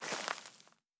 added stepping sounds
Undergrowth_Mono_02.wav